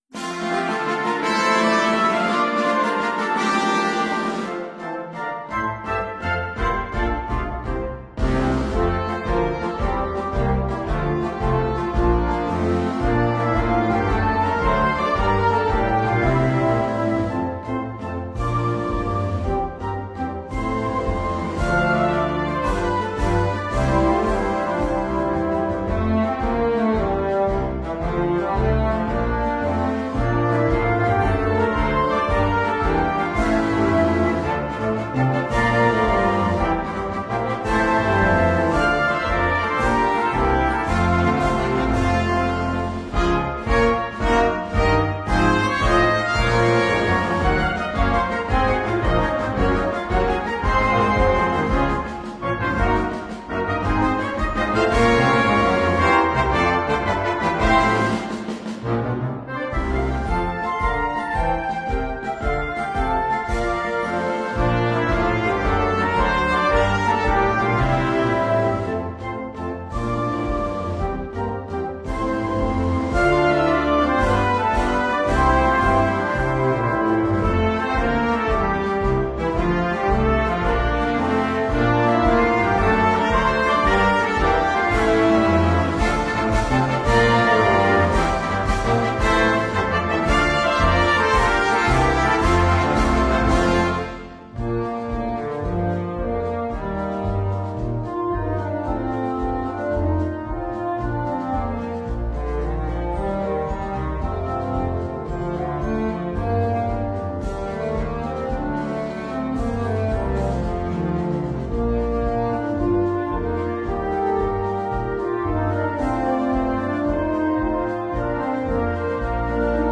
Gattung: Marsch
3:00 Minuten Besetzung: Blasorchester PDF